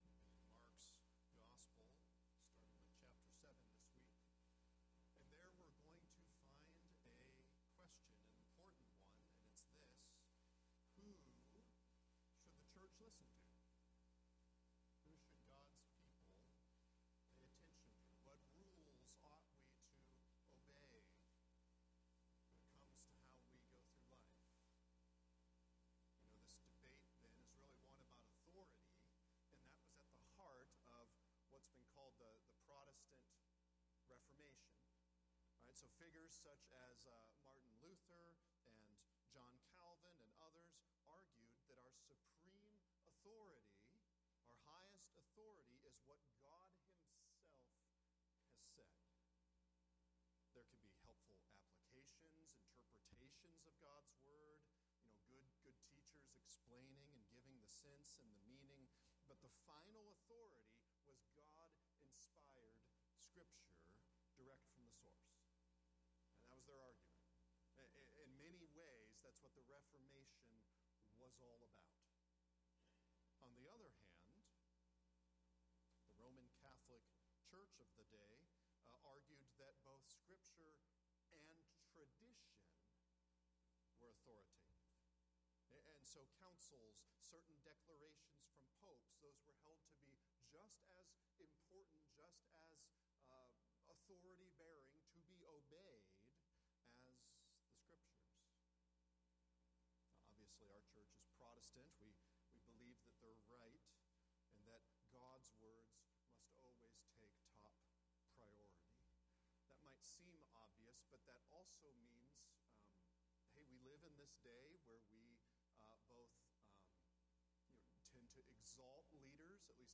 Mark 7:1-23 Heart Problems – Sermons